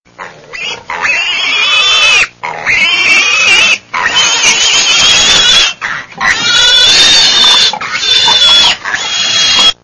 جلوه های صوتی
دانلود صدای خوک از ساعد نیوز با لینک مستقیم و کیفیت بالا